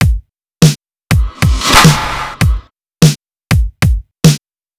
CAT RATS DRUM LOOPfinal.wav